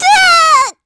Ripine-Vox_Damage_kr_04.wav